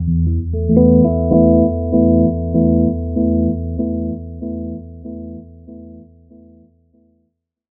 Rhodes_1.wav